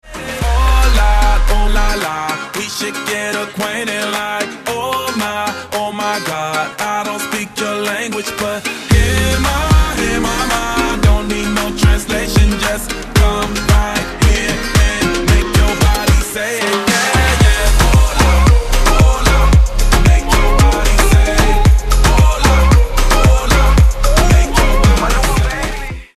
• Качество: 320, Stereo
поп
позитивные
мужской вокал
зажигательные
заводные
dance